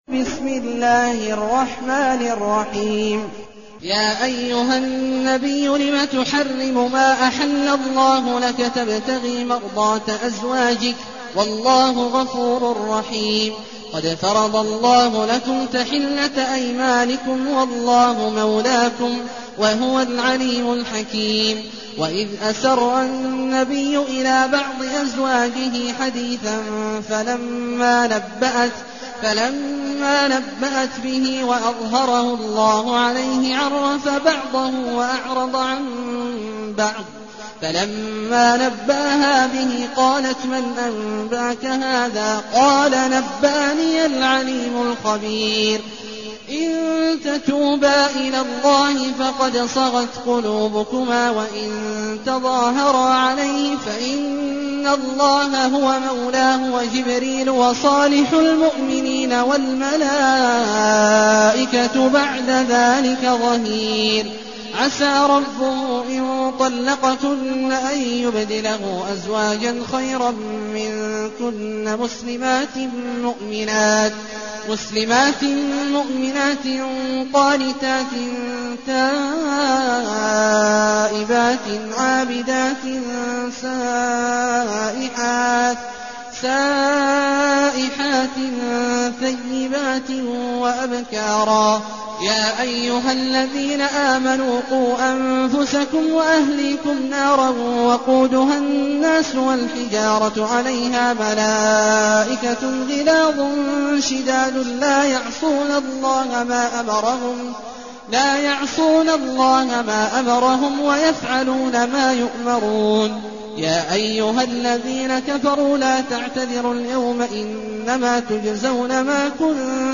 المكان: المسجد الحرام الشيخ: عبد الله عواد الجهني عبد الله عواد الجهني التحريم